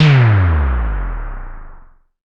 drop.wav